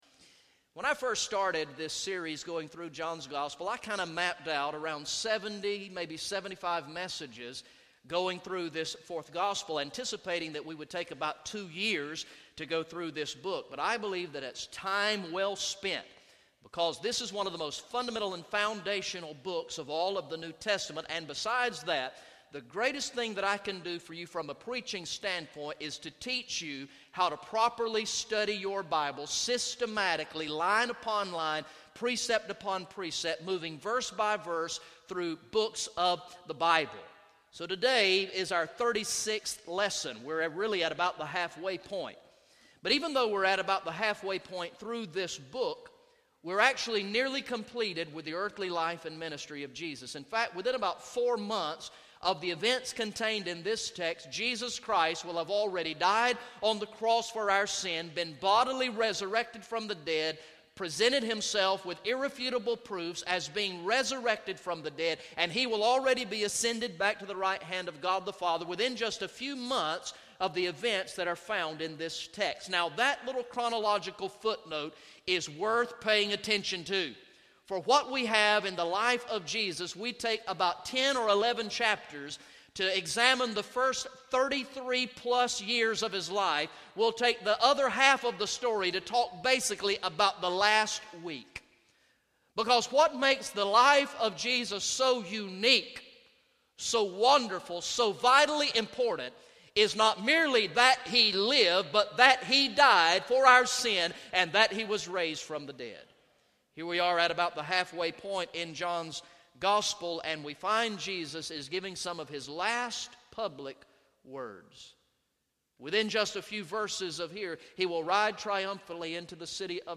Message #36 from the sermon series through the gospel of John entitled "I Believe" Recorded in the morning worship service on Sunday, March 22, 2015